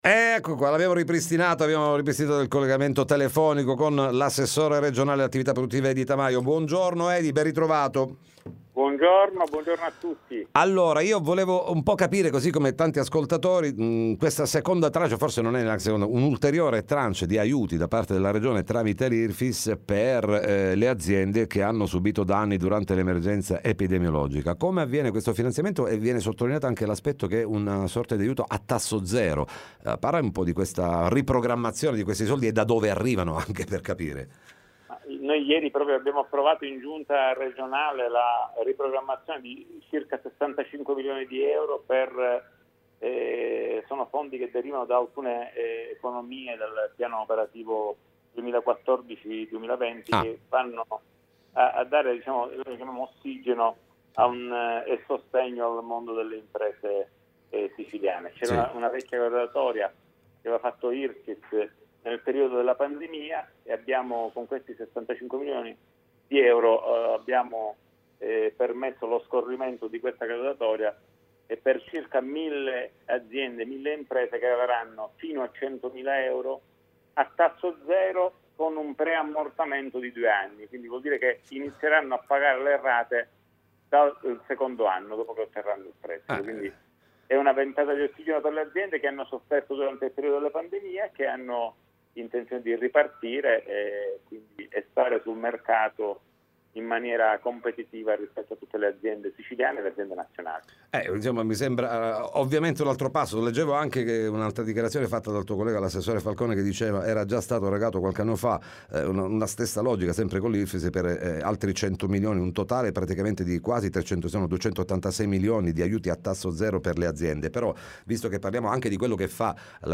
Finanziamenti di 65 milioni di euro per aiutare mille imprese siciliane. ne parliamo con Edy Tamajo, Ass. Reg. Att. Produttive